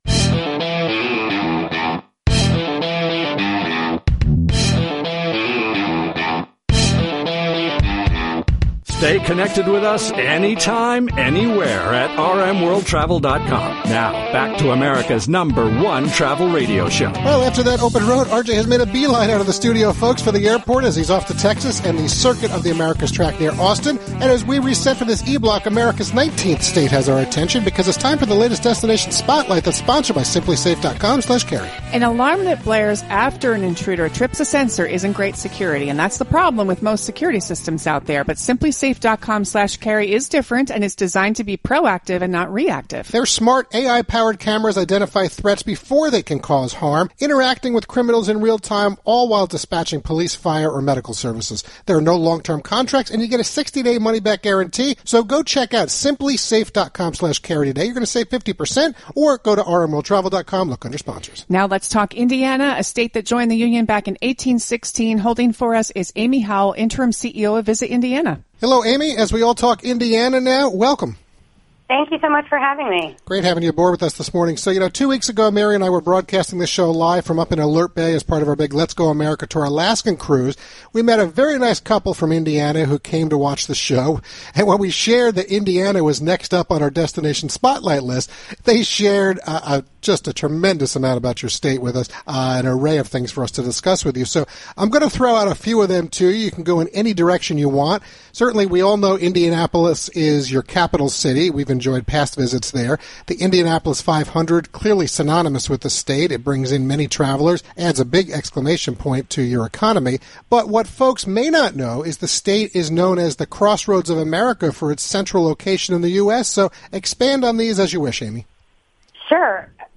During our live national broadcast of America’s #1 Travel Radio on September 6th — America’s 19th State aka “The Hoosier State” was featured…